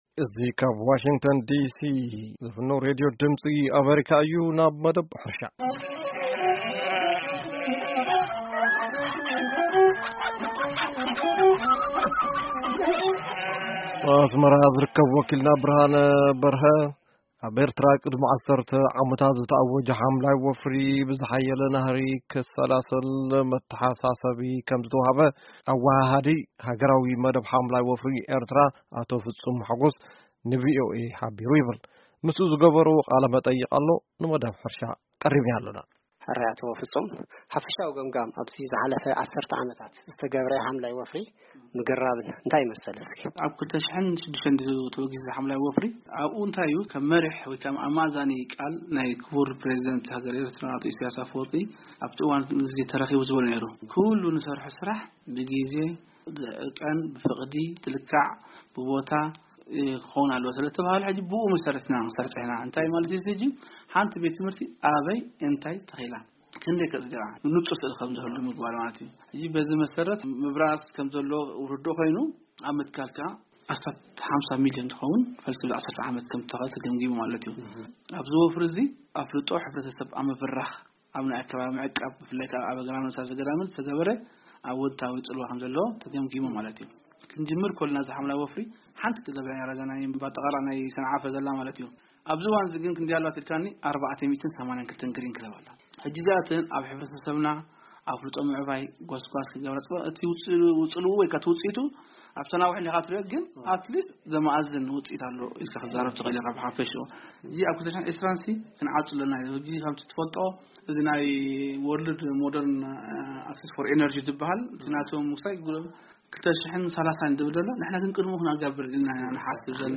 ቃለ-መጠይቅ